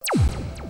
Звуки лазерного оружия
Звук выстрела лазера